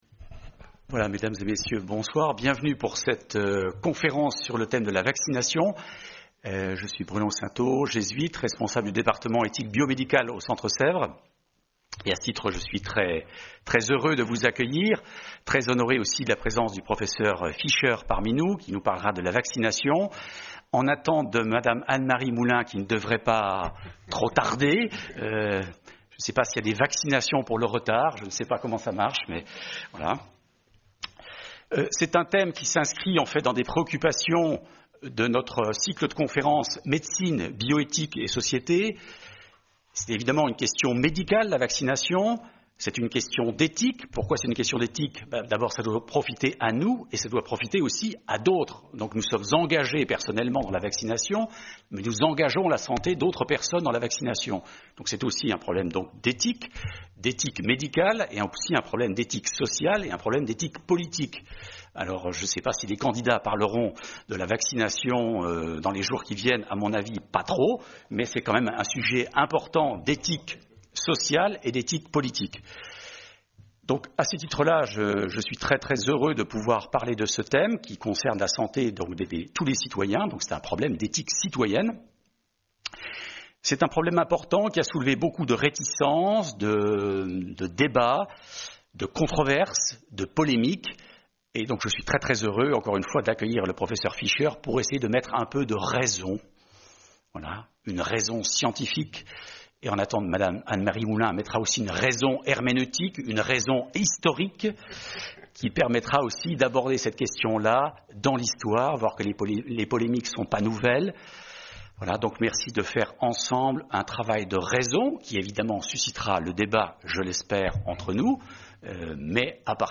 Colloque Médecine, Bioéthique et Société avec Pr.